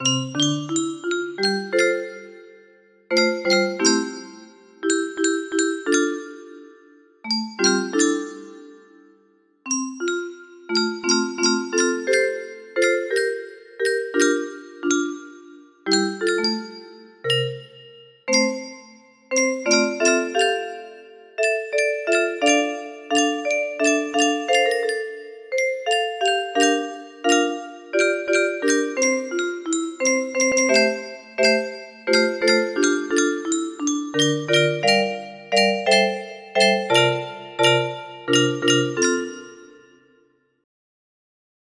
Clone of Refrein 30 tonen music box melody